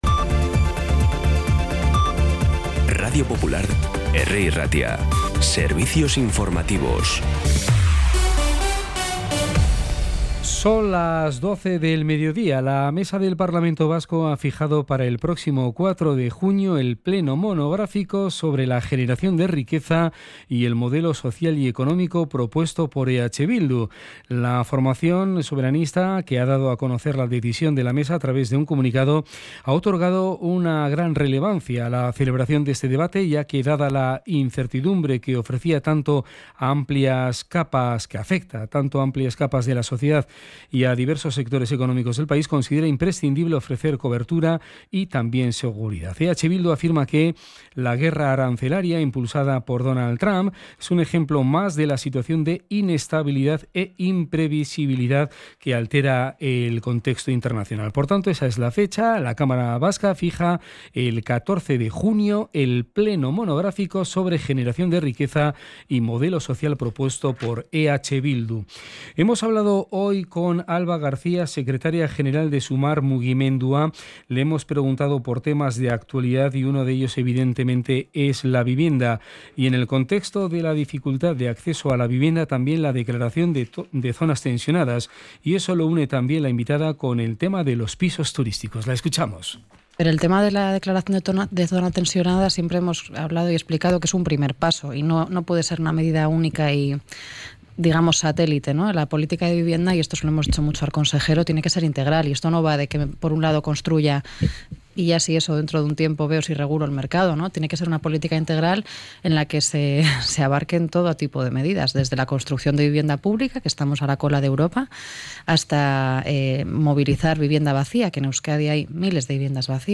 Las noticias de Bilbao y Bizkaia del 13 de mayo a las 12
Los titulares actualizados con las voces del día. Bilbao, Bizkaia, comarcas, política, sociedad, cultura, sucesos, información de servicio público.